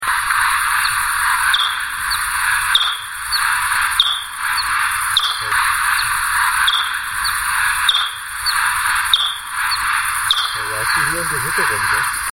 Fledermäuse
Fledermaeuse-Michelbach.mp3